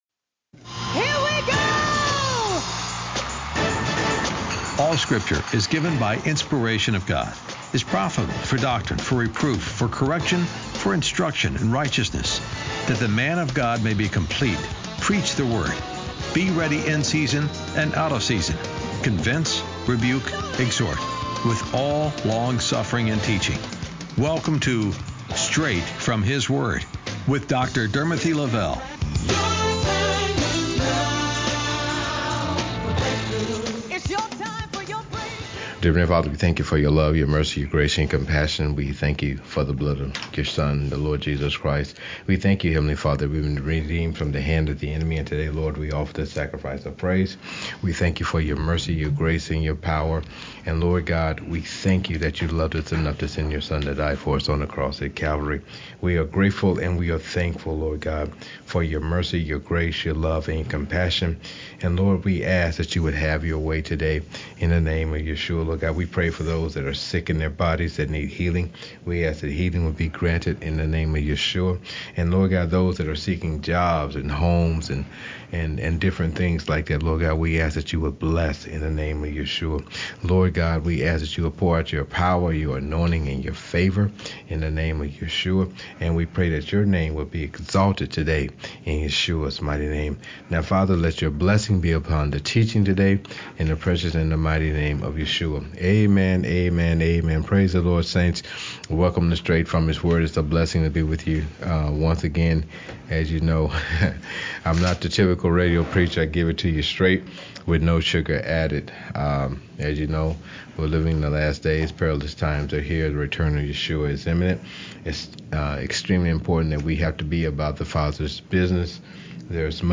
Special Guests Boeing Interview